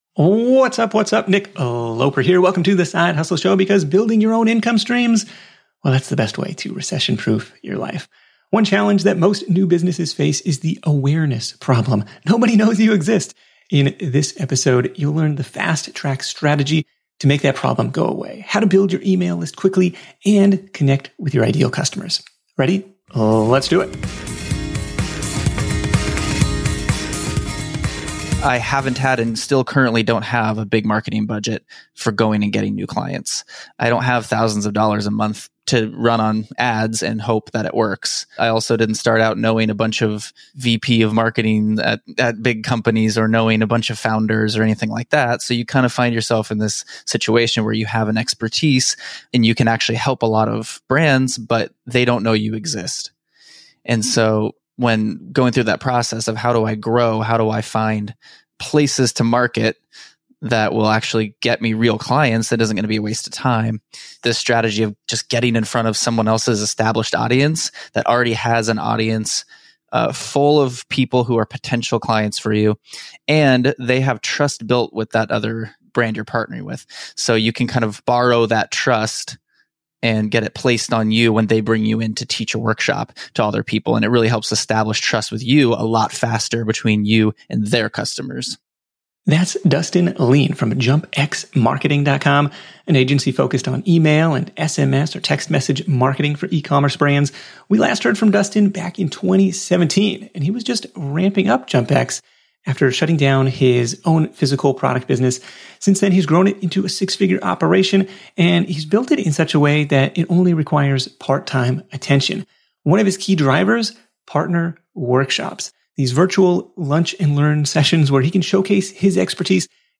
Tune in to The Side Hustle Show interview